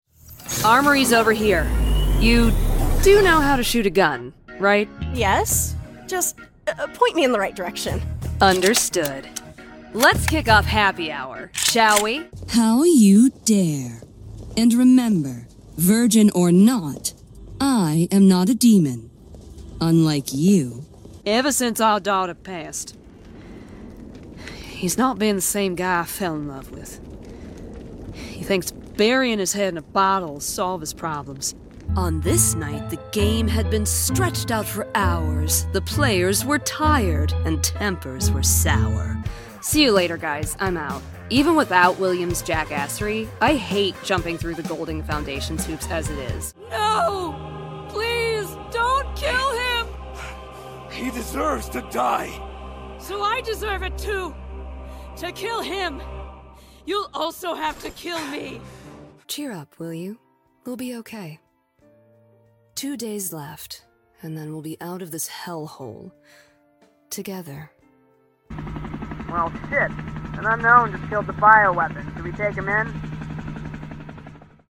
southern us | natural
standard british | character
standard us | natural
GAMING 🎮